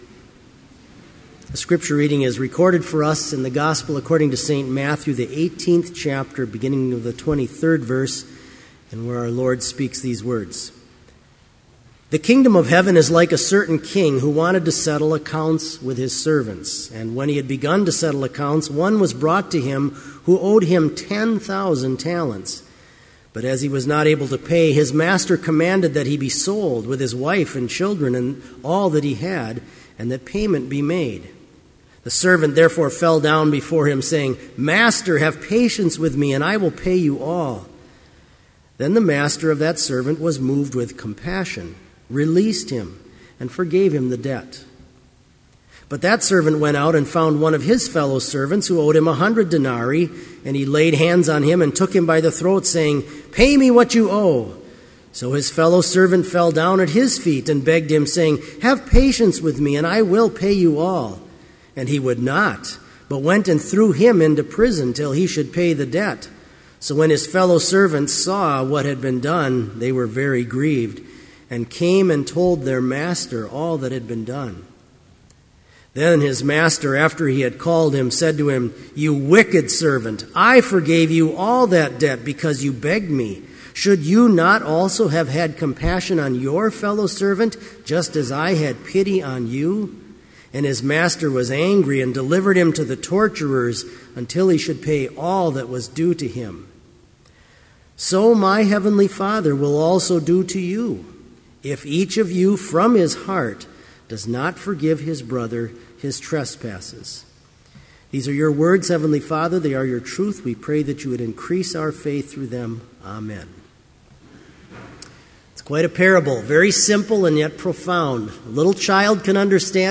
Complete service audio for Chapel - November 7, 2012